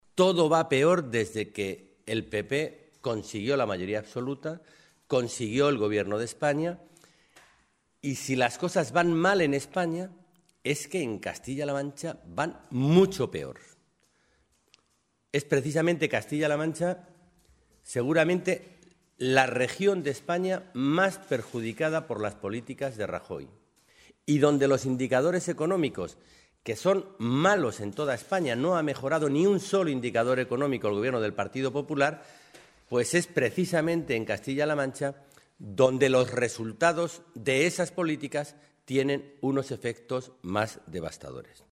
En una comparecencia en Toledo ante los medios de comunicación, Alonso ha resumido la mitad de la legislatura del PP diciendo que “dos años después tenemos más paro, más deuda y más pobreza”.
Cortes de audio de la rueda de prensa